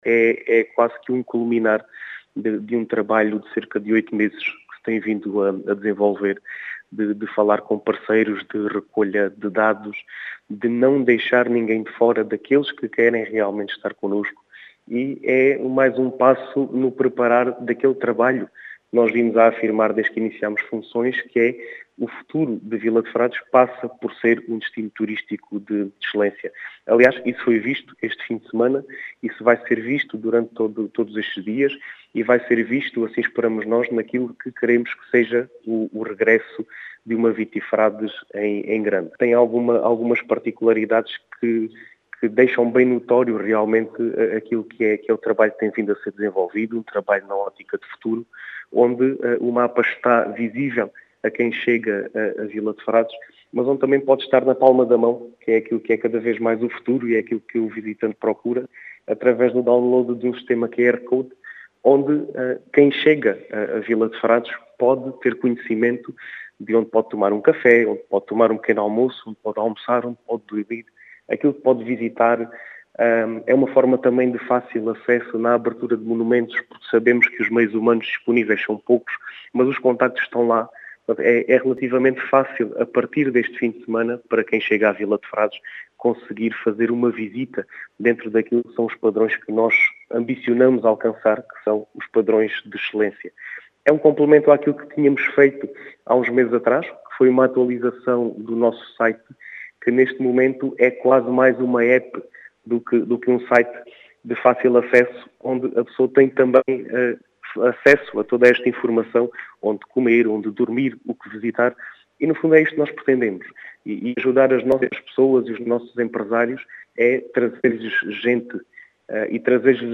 As explicações são do presidente da junta de freguesia de Vila de Frades, Diogo Conqueiro, que quer a freguesia como um destino “vínico de excelência”.